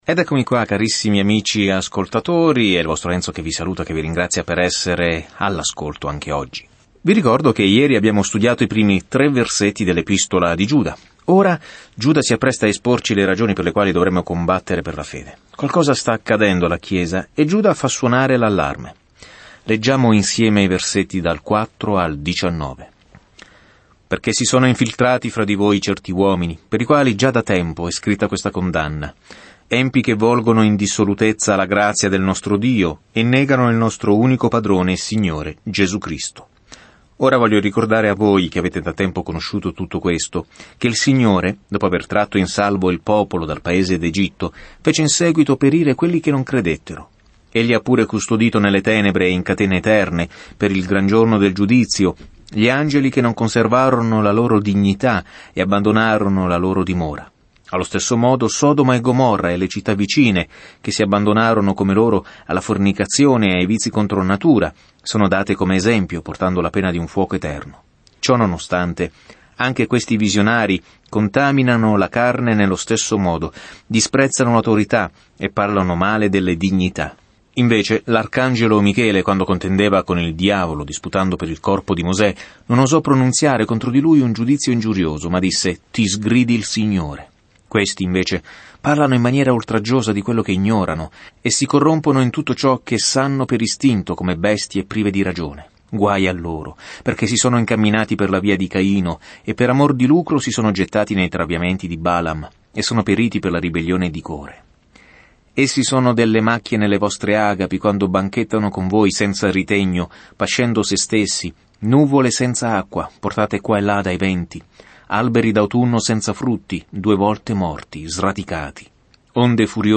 Scrittura Lettera di Giuda 1:4 Giorno 2 Inizia questo Piano Giorno 4 Riguardo questo Piano “Lotta per la fede” dice questa breve ma diretta lettera di Giuda ai cristiani che lottano contro i falsi maestri che si sono insinuati nella chiesa inosservati. Viaggia ogni giorno attraverso Giuda mentre ascolti lo studio audio e leggi versetti selezionati della parola di Dio.